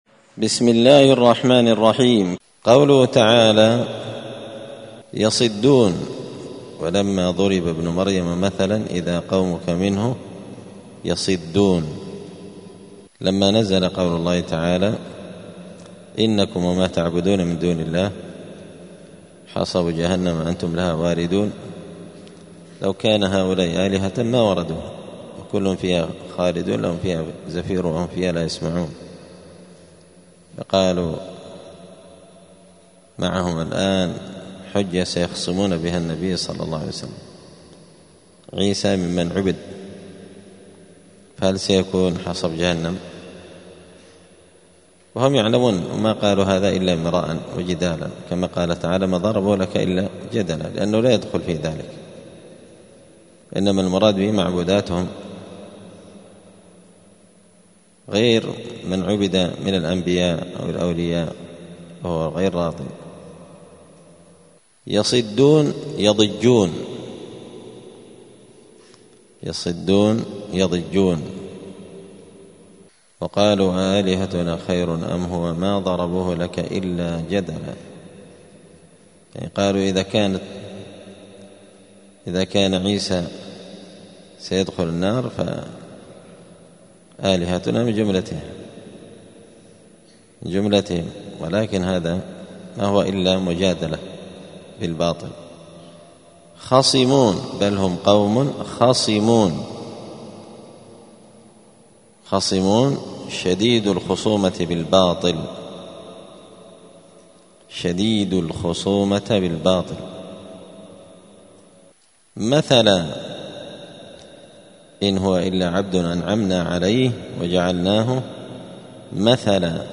*(جزء فصلت سورة الزخرف الدرس 239)*